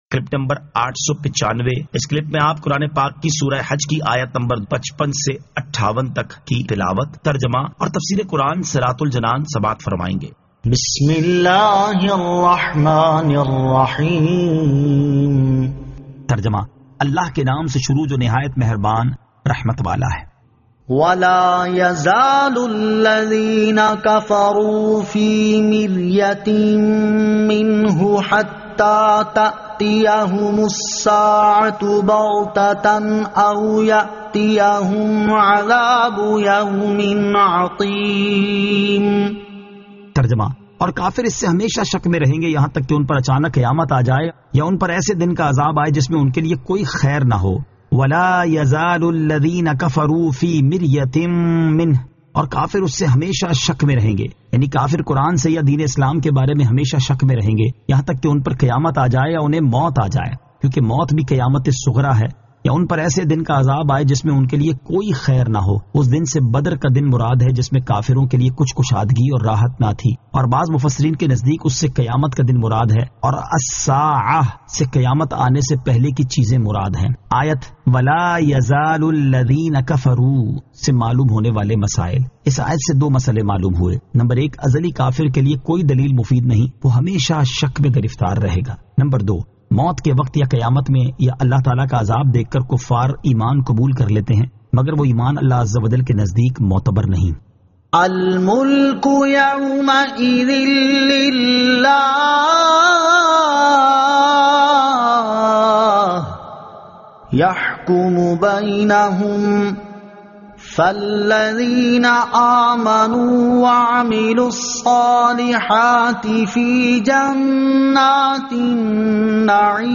Surah Al-Hajj 55 To 58 Tilawat , Tarjama , Tafseer